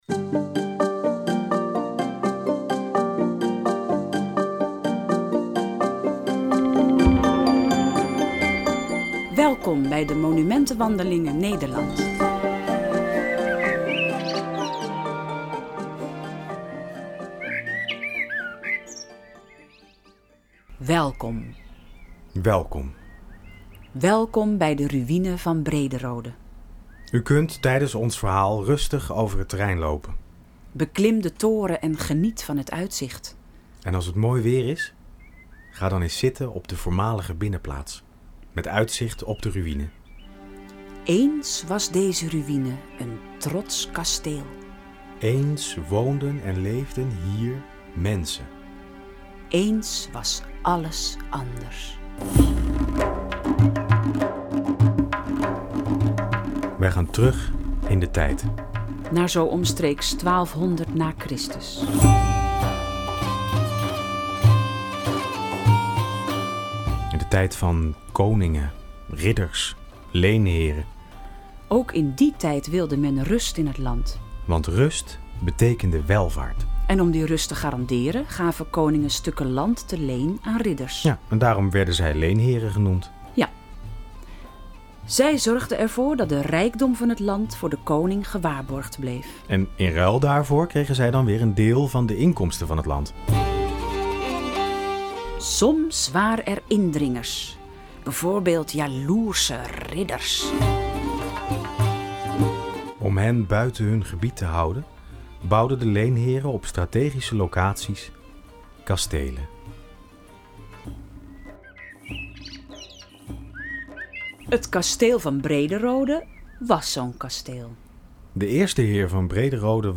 De Ruine van Brederode ligt in Santpoort vlakbij Haarlem. Dit hoorspel is opgenomen als demo voor Geluid van Toen.
Een kort semi-educatief hoorspel, wat vooral voor de lol gemaakt is.